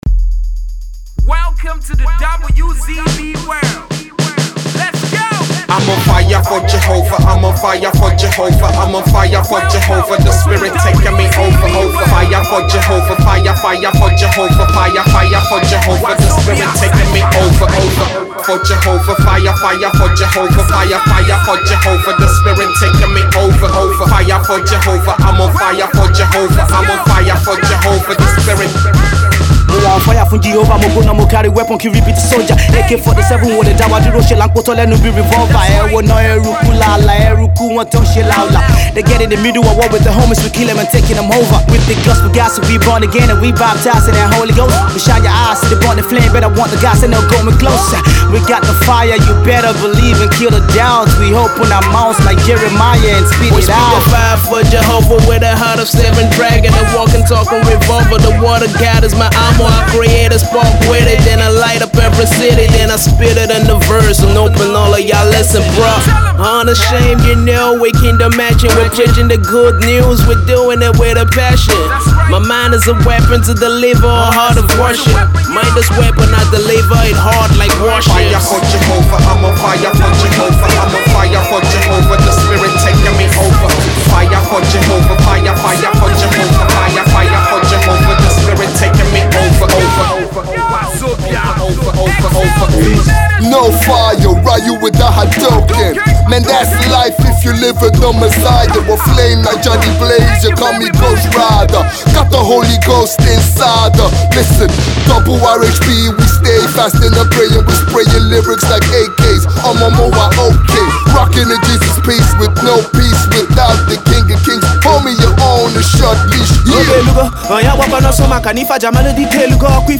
a Christian song